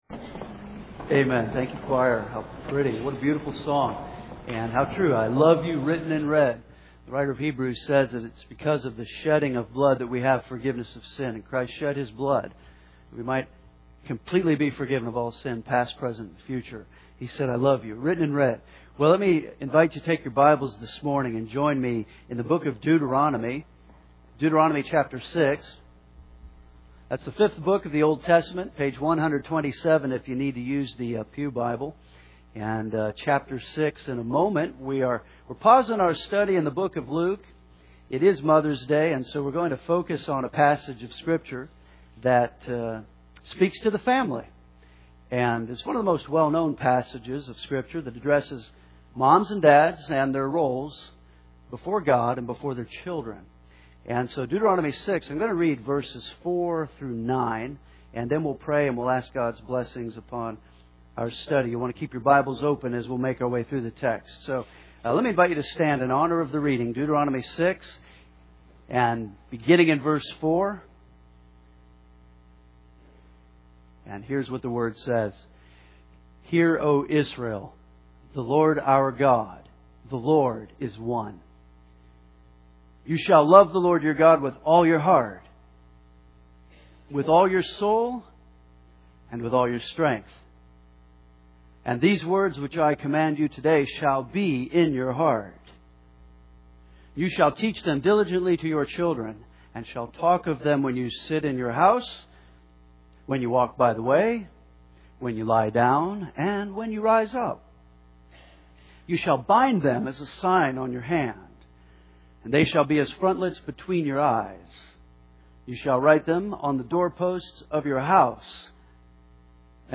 And many times nothing makes its way into the sermon as it just didn’t fit.